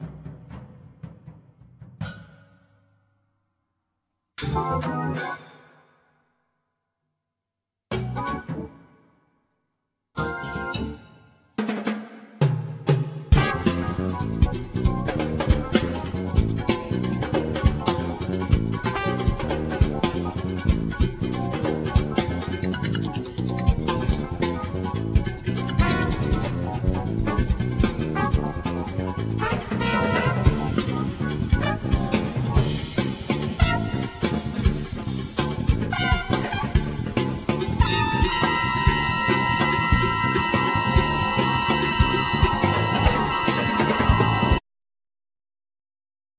Trumpet
Piano & Keyboard
Basses
Percussion,Drums,Voice